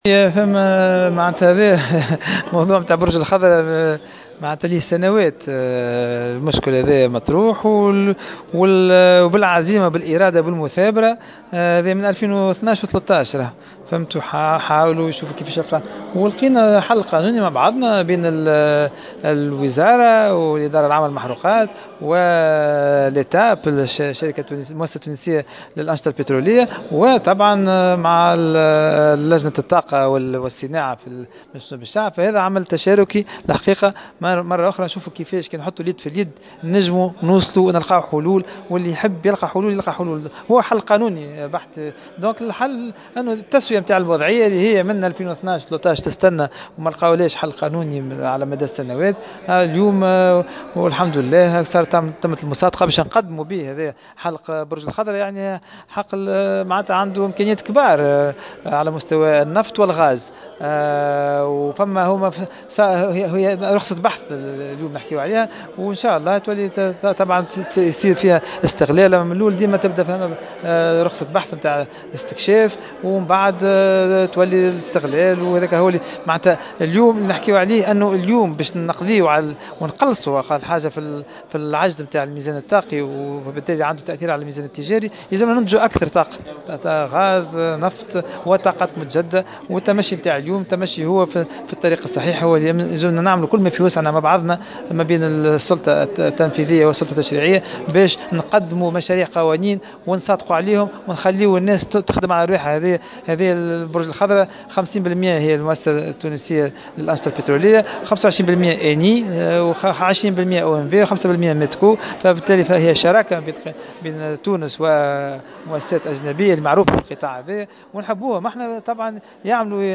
وفي تصريح لمراسلة "الجوهرة اف أم"، وصف وزير الصناعة سليم الفرياني، المصادقة على المشروع باليوم التاريخي، موضحا أن مشكلة هذه الرخصة تعود الى 2012 وتم التوصل بالشراكة بين مختلف مؤسسات الدولة المعنية إلى حل قانوني.